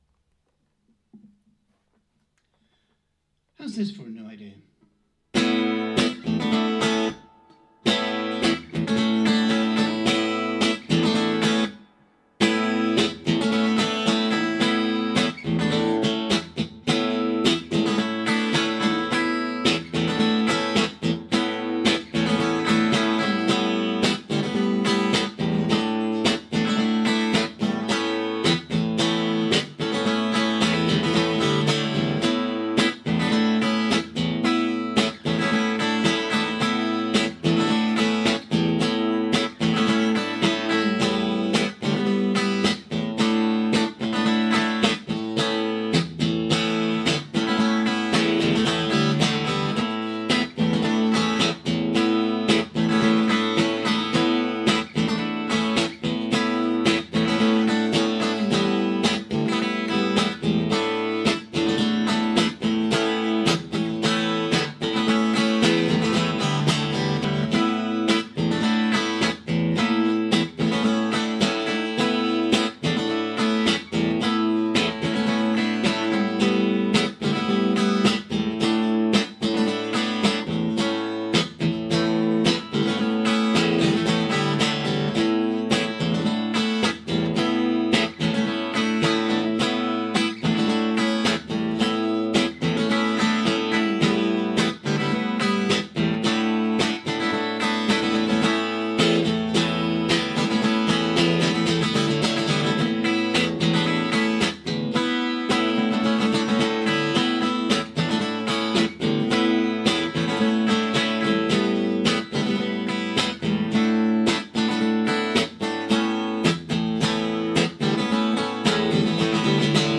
guitar riff idea ...